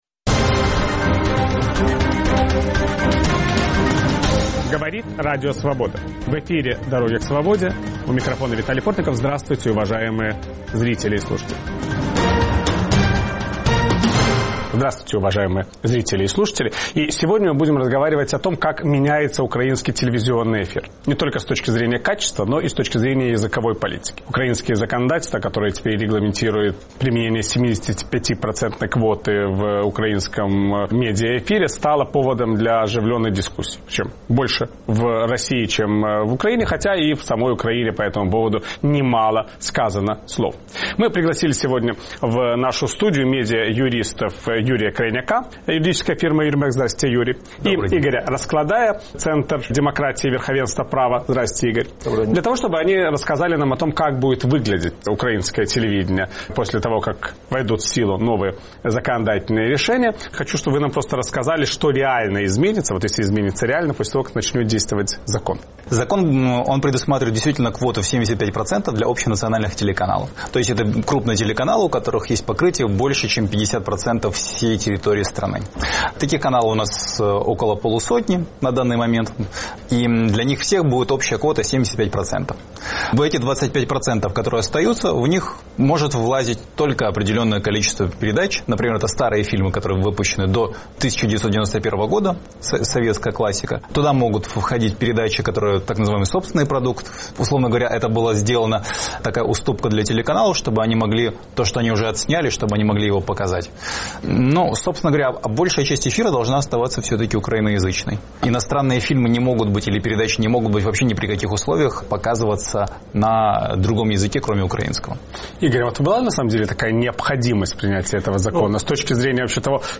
Как будет действовать украинское законодательство о языковых квотах на телевидении? Виталий Портников беседует с медиа-юристами